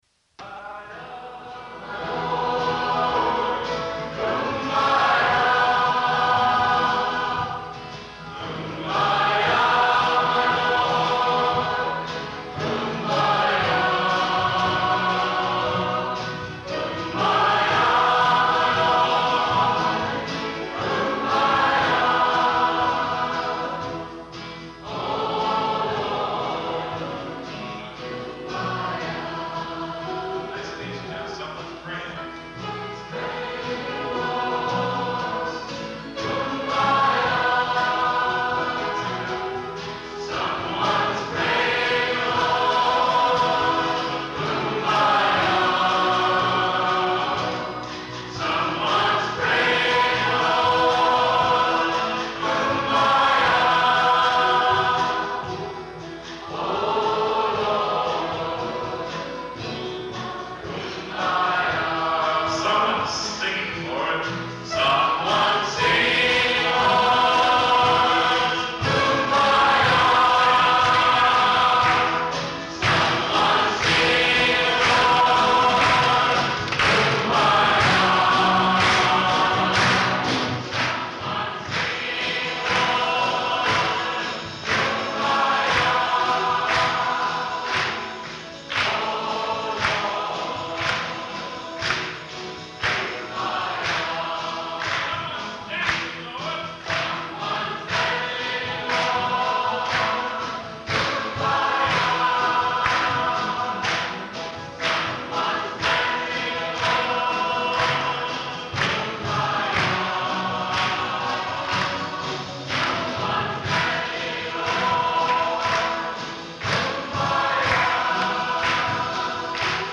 3.25 ips - live concert Hear & Now
Labeled Magog Concert United Church